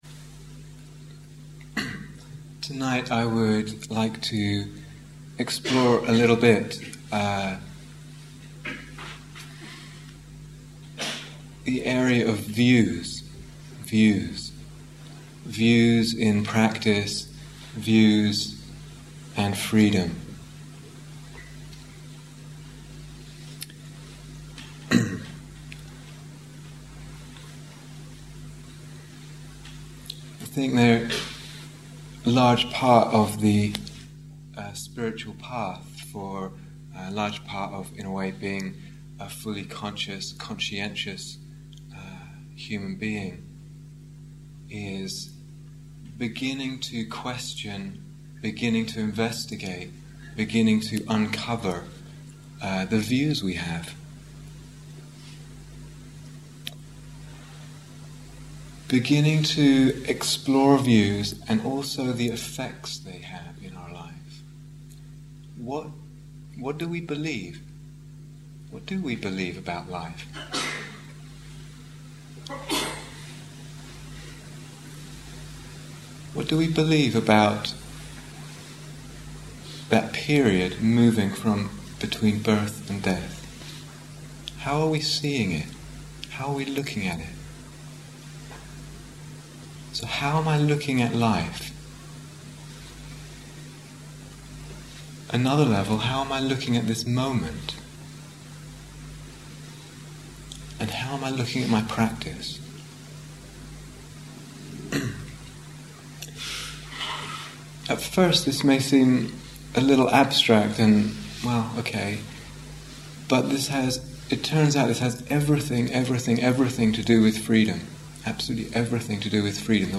Retreat/Series New Year Retreat 2006